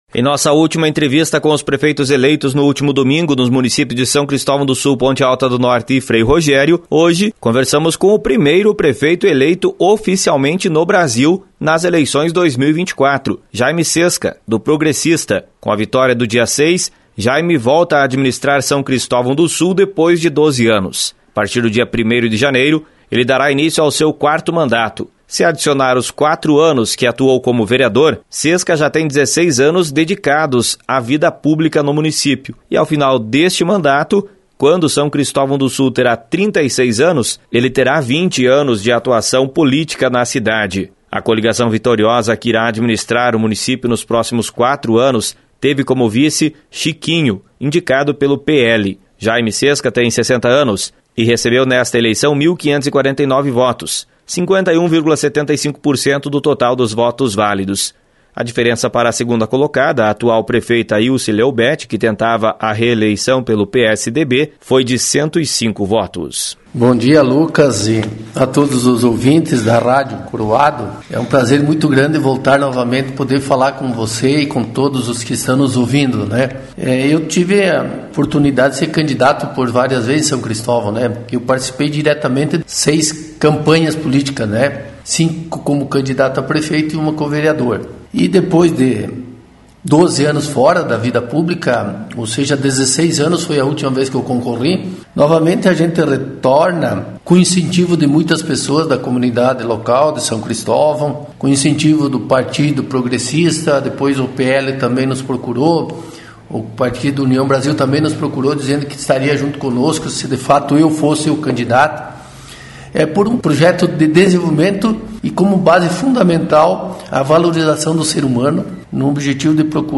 Jaime Cesca, o primeiro prefeito eleito no Brasil em 2024, fala a Rádio Coroado